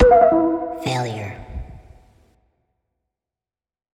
Failure_V2.wav